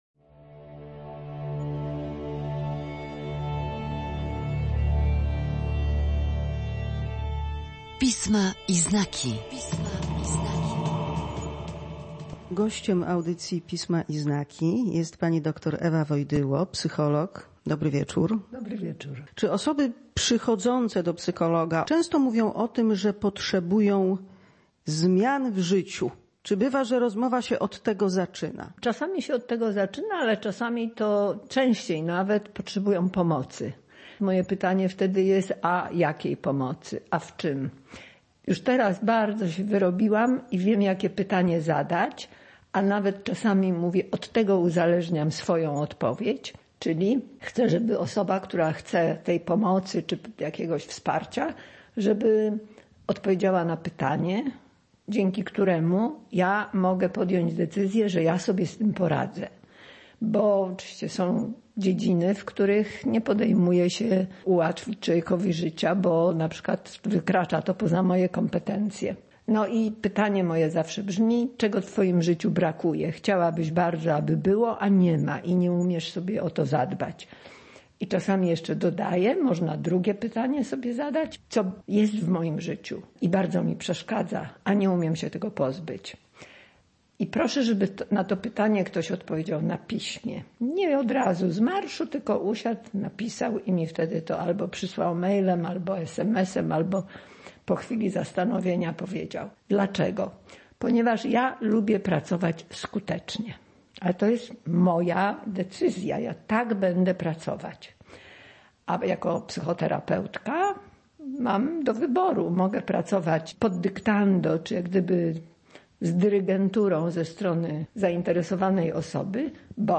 W audycji Pisma i znaki rozmawiałyśmy o doświadczeniach, które mogą stanowić impuls, by dokonać zmian w swoim życiu. Co nas inspiruje, a co utrudnia lub nawet czasem uniemożliwia takie działania?